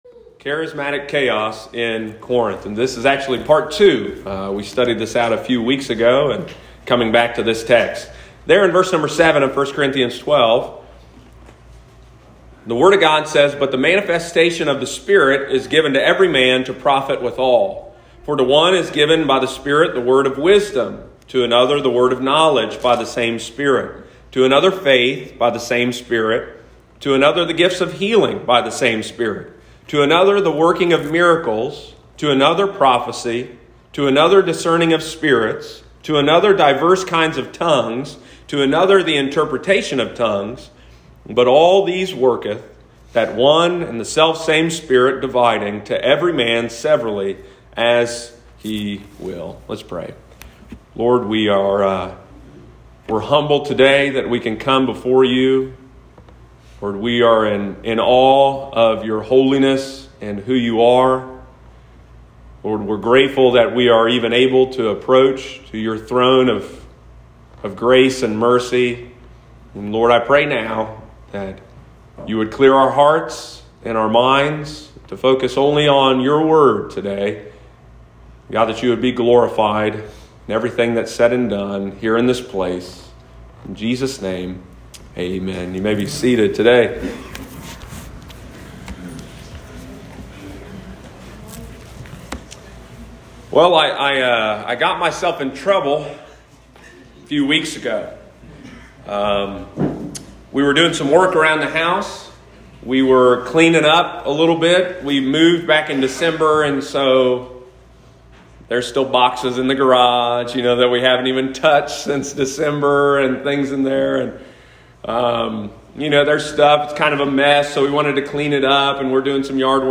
Sunday morning, August 29, 2021.